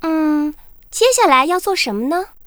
文件 文件历史 文件用途 全域文件用途 Daphne_amb_03.ogg （Ogg Vorbis声音文件，长度0.0秒，0 bps，文件大小：32 KB） 源地址:游戏语音 文件历史 点击某个日期/时间查看对应时刻的文件。